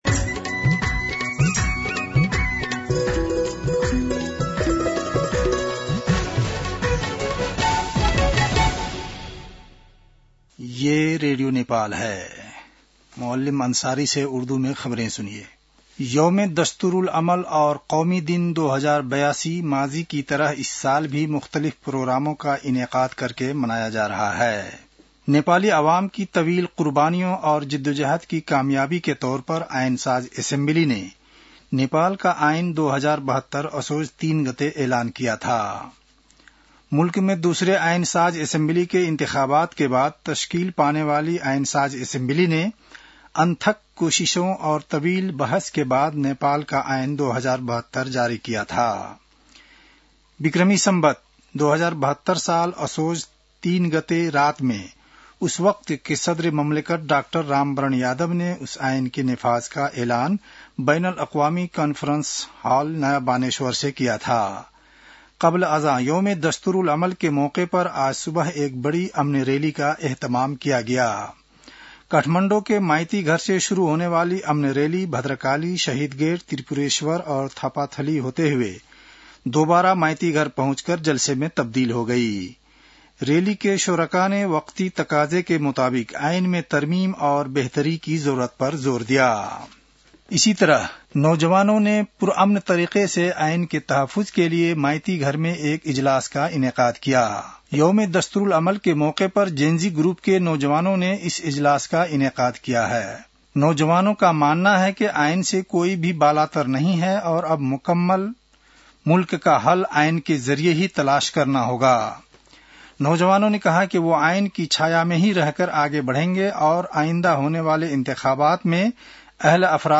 उर्दु भाषामा समाचार : ३ असोज , २०८२